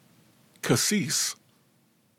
THE MOST COMMONLY USED LOCAL PRONUNCIATIONS
Casis (kuh-SEES) Elementary School-- "Old school" old school in pricy West Austin.